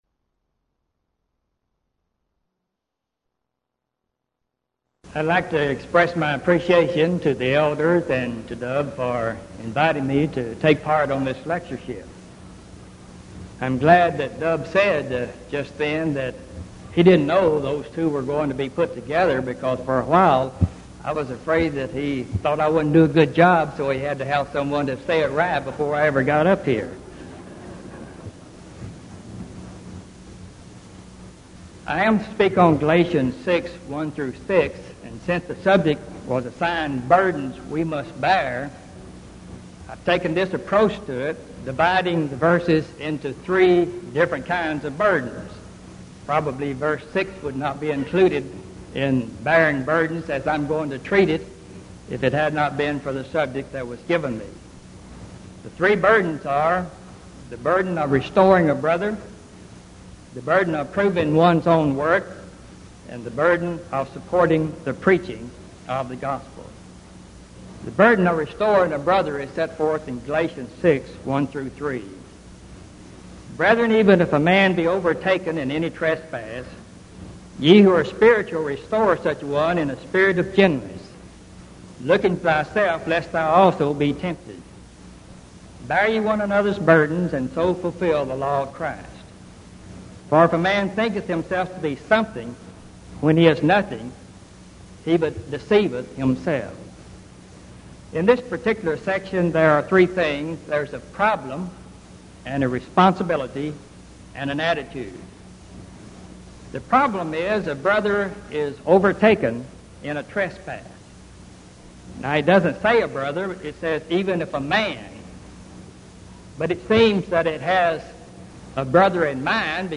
Event: 1986 Denton Lectures Theme/Title: Studies in Galatians